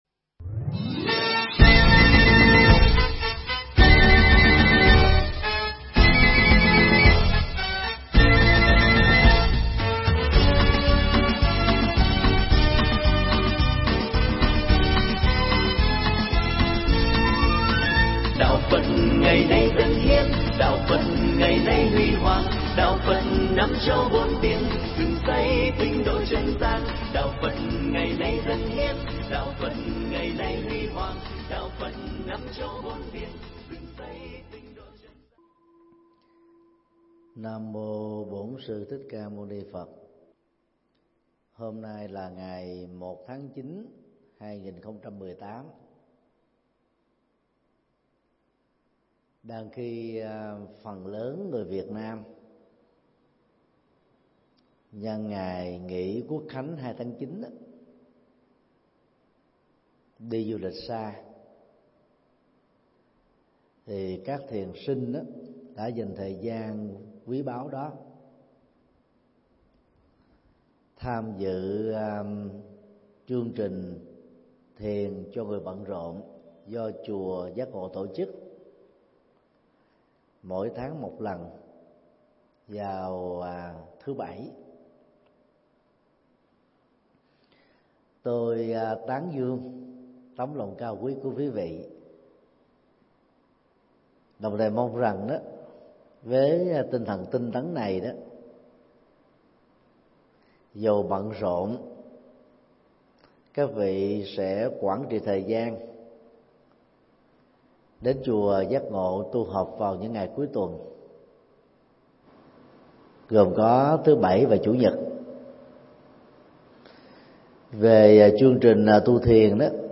Mp3 Pháp Thoại Chánh Niệm Với Quán Vô Thường, Quán Lìa Tham, Quán Đoạn Diệt Và Buông Xả – Thượng Tọa Thích Nhật Từ giảng trong khóa tu thiền cho người bận rộn kỳ 4, tại chùa Giác Ngộ ngày 1 tháng 9 năm 2018